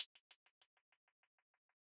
Hihat Roll 1.wav